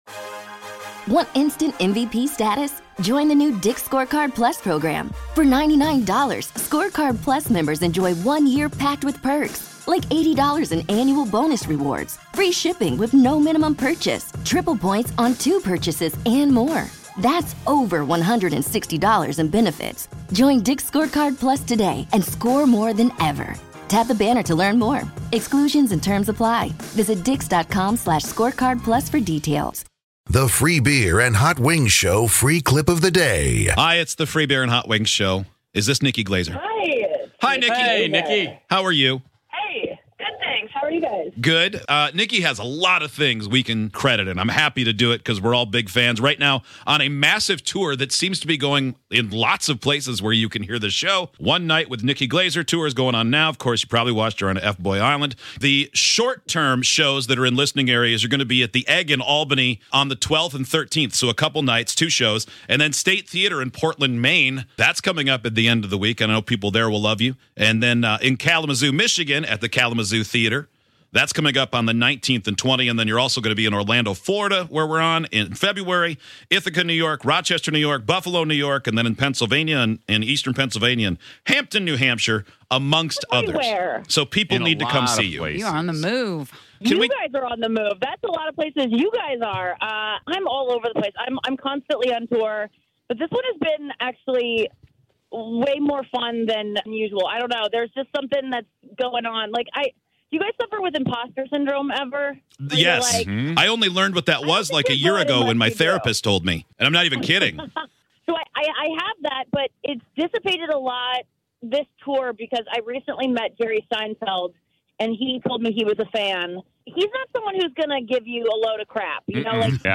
Our Interview With The Hilarious & Talented Nikki Glaser
On today's show, we interviewed comedian Nikki Glaser and she had us cracking up. What new stuff is she trying for her tour and why in the world did she take her shirt off in front of thousands of people?